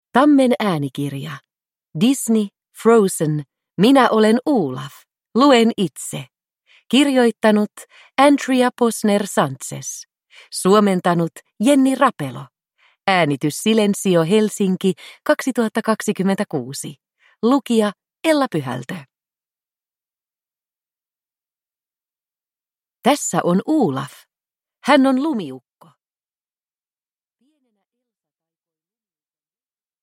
Disney. Frozen. Minä olen Olaf! Luen itse – Ljudbok